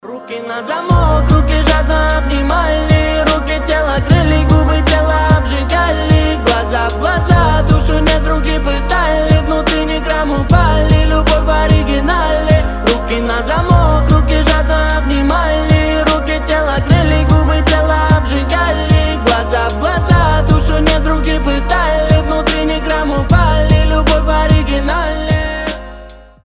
• Качество: 320, Stereo
мужской голос
лирика
Хип-хоп